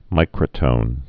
(mīkrə-tōn)